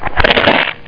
1 channel
crunchb.mp3